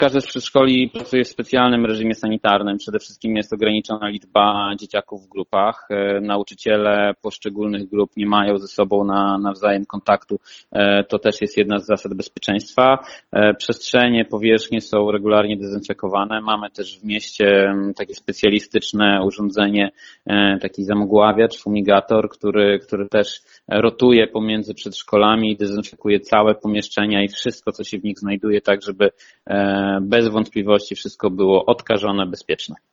– W poniedziałek (22.06.20) otrzymaliśmy dobre informacje, w związku z tym od wtorku (23.06.20) uruchomiona zostanie praca wszystkich grup przedszkolnych – poinformował Radio 5 Filip Chodkiewicz, zastępca burmistrza Augustowa.